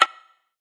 Percs
DDW Perc 4.wav